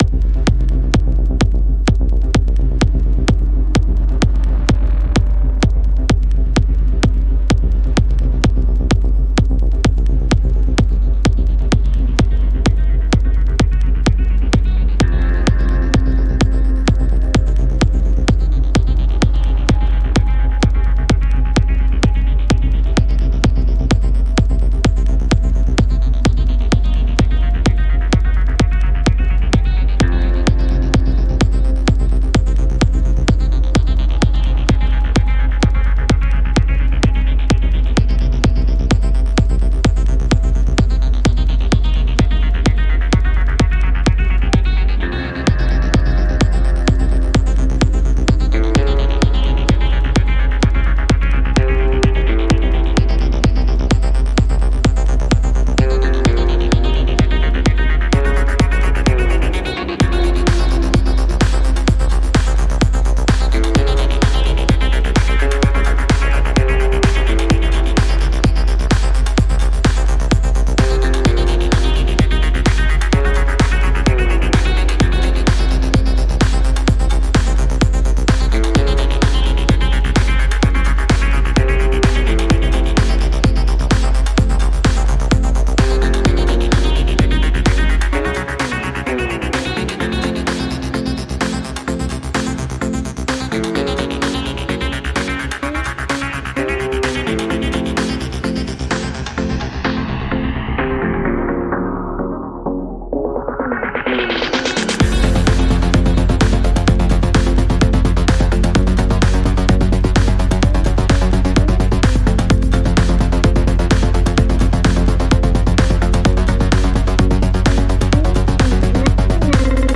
Nächster Versuch (Techno)